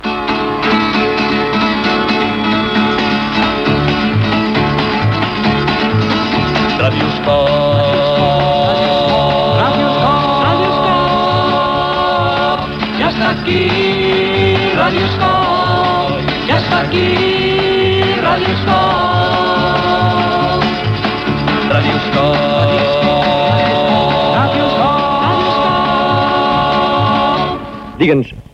Segona sintonia del programa